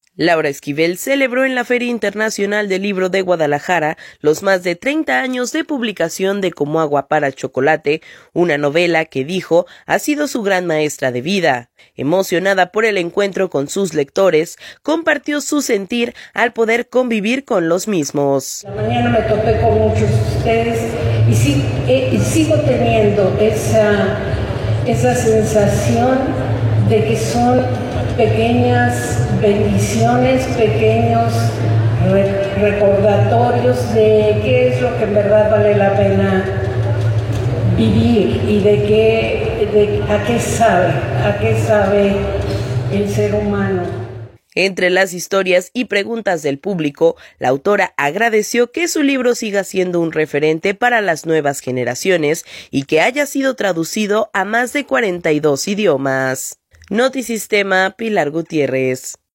Laura Esquivel celebró en la Feria Internacional del Libro de Guadalajara los más de 30 años de publicación de “Como agua para chocolate”, una novela que, dijo, ha sido su gran maestra de vida.